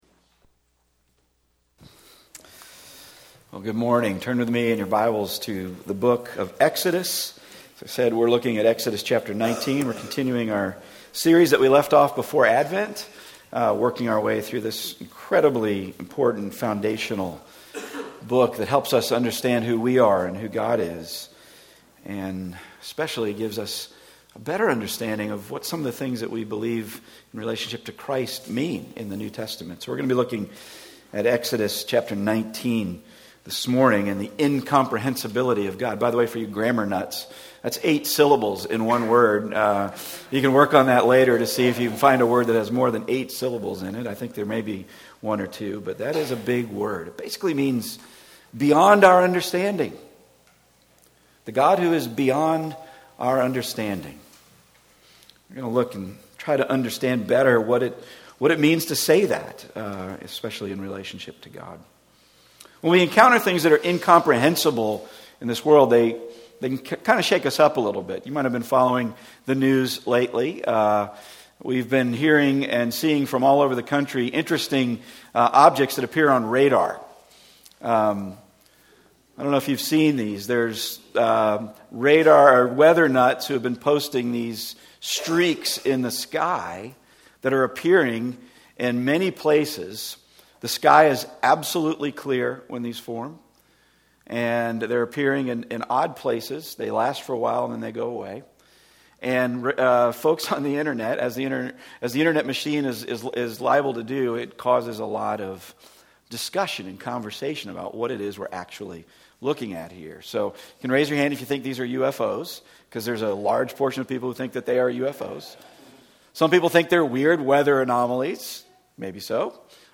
Service Type: Weekly Sunday